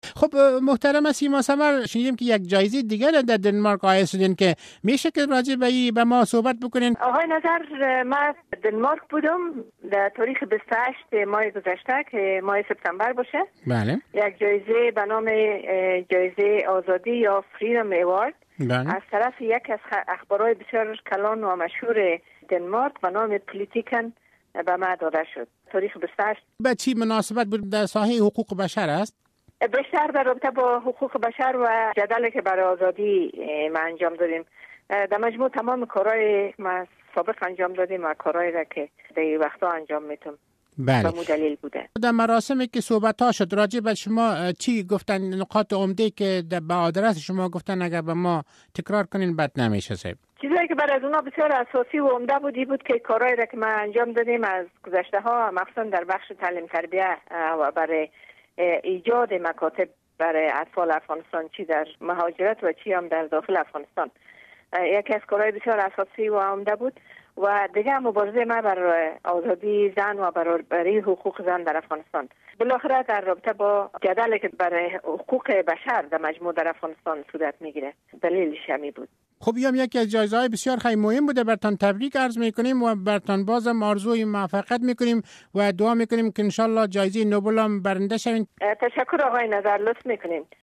مصاحبه با سیما ثمر